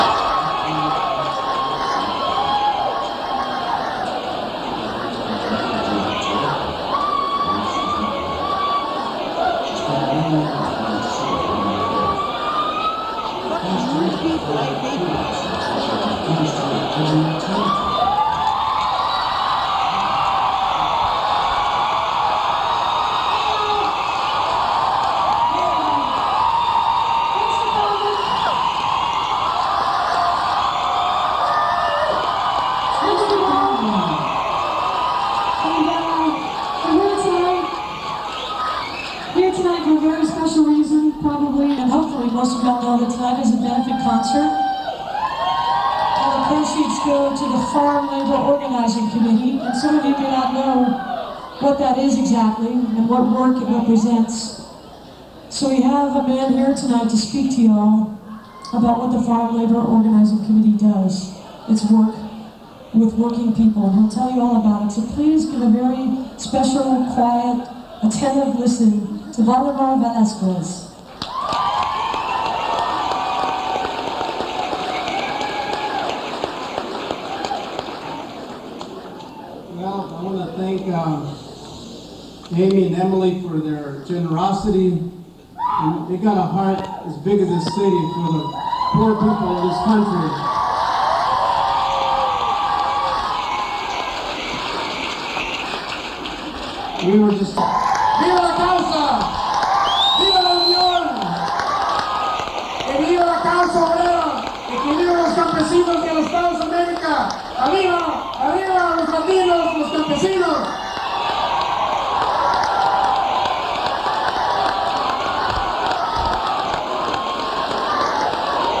02. talking with the crowd (1:58)